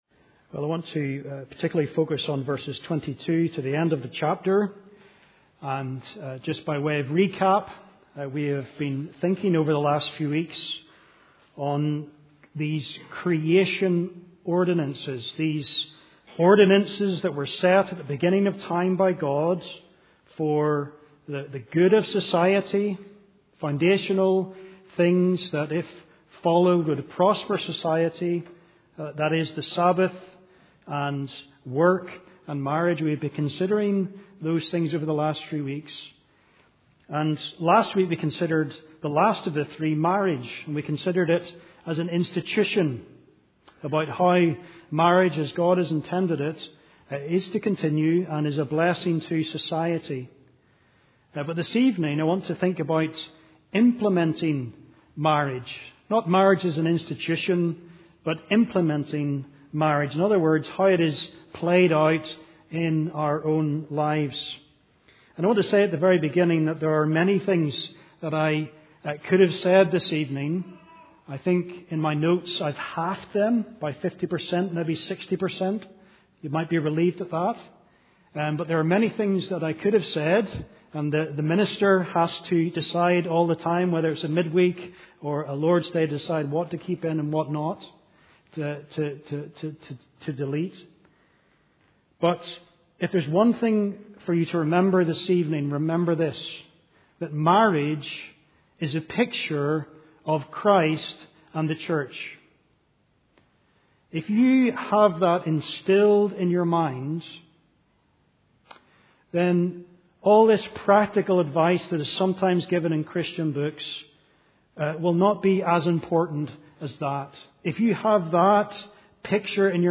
Sermons - Immanuel Presbyterian Church